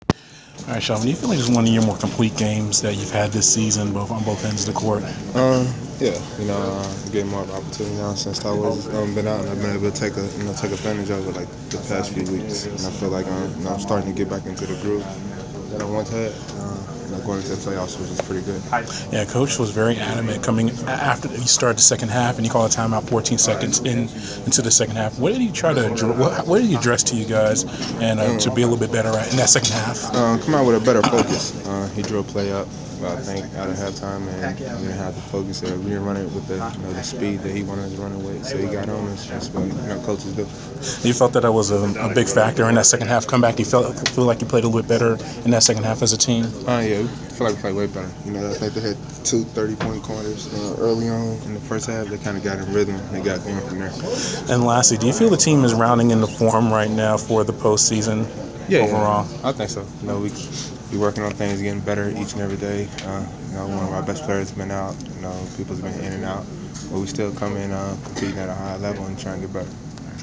Inside the Inquirer: Postgame interview with Atlanta Hawks’ Shelvin Mack (4/14/15)
We spoke with Atlanta Hawks’ guard Shelvin Mack for an exclusive interview after his team’s 112-108 loss to the New York Knicks on April 14.